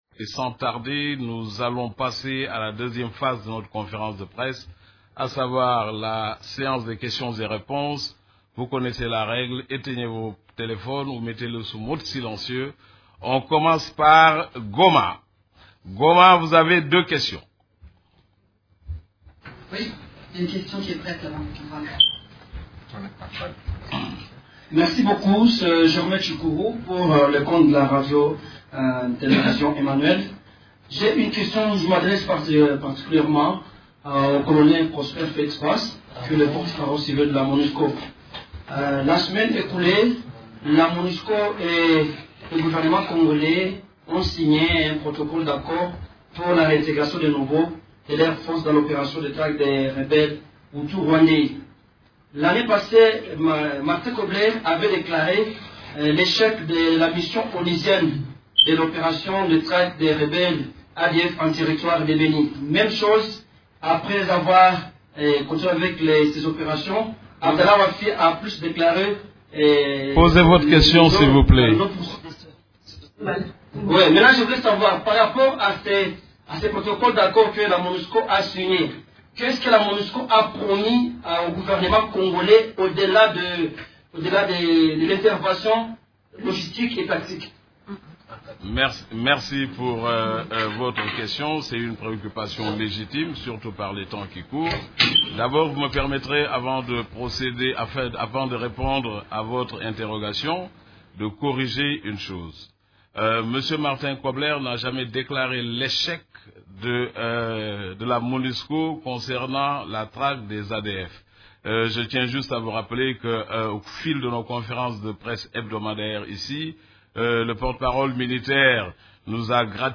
Conférence de presse du 3 février 2016
Les activités des composantes de la Monusco et la situation militaire ont été au centre de la conférence de presse hebdomadaire des Nations unies du mercredi 3 février à Kinshasa.
Voici la première partie de la conférence de presse: